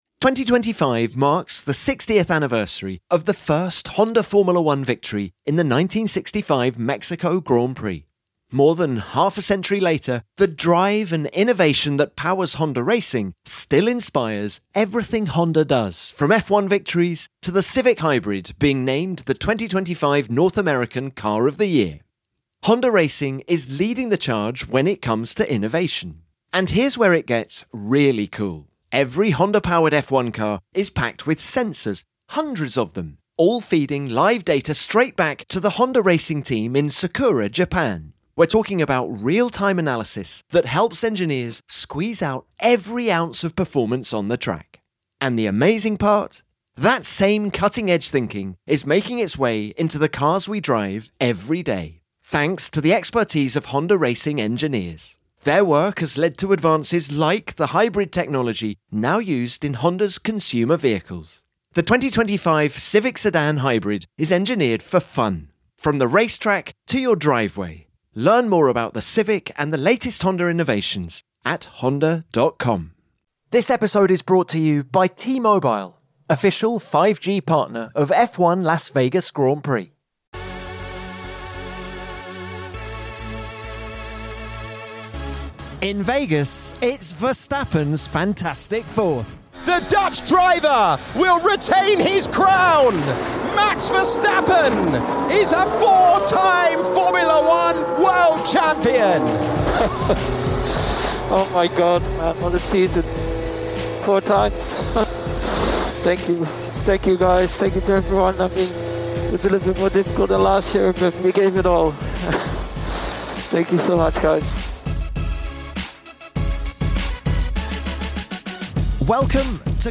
while Red Bull Team Principal Christian Horner explains how Max has made the difference this season.